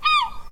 sounds_seagull_02.ogg